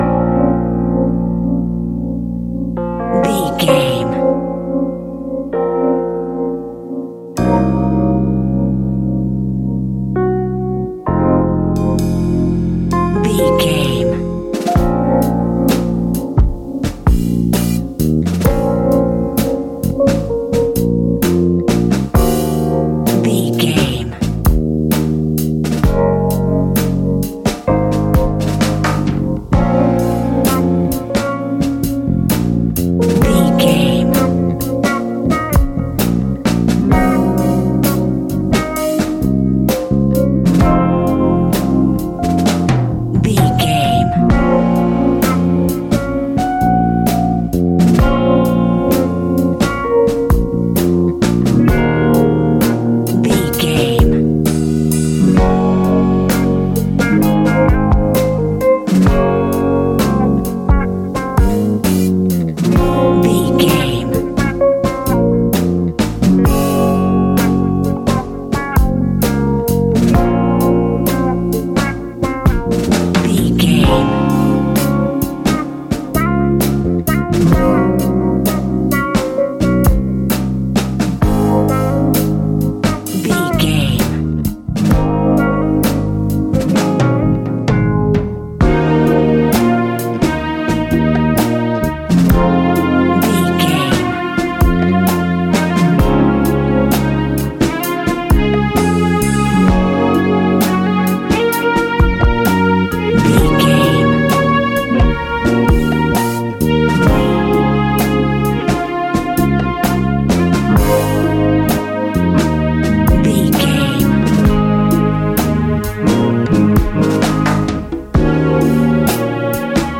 Ionian/Major
A♯
hip hop
instrumentals